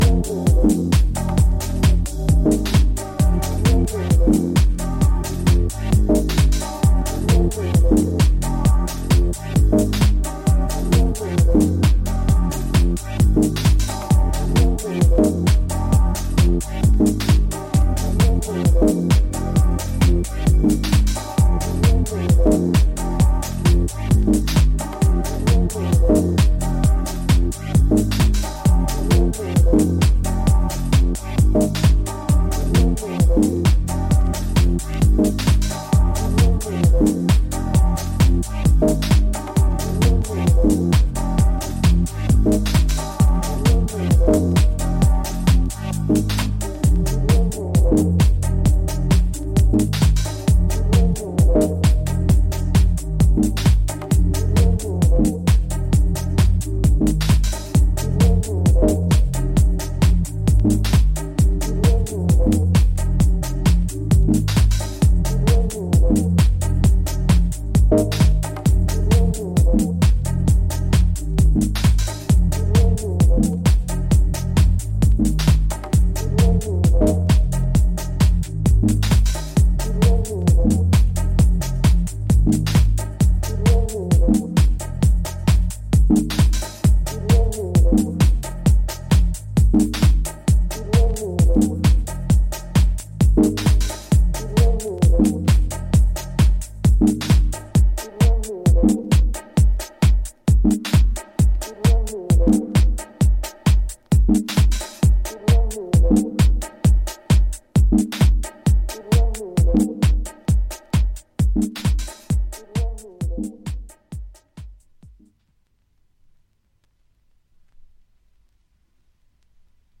シルキーかつヒプノティックな、タイムレスな魅力を放つディープ・テック・ハウス